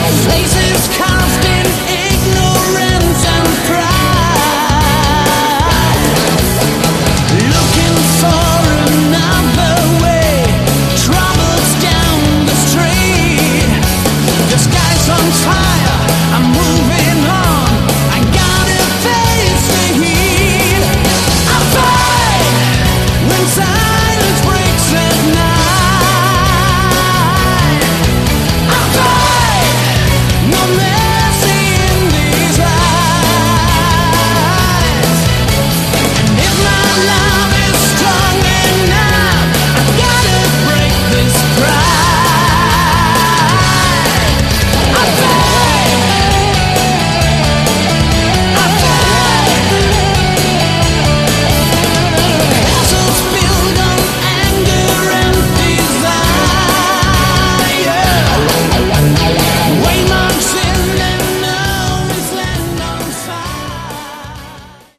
Category: Hard Rock
vocals
bass
guitar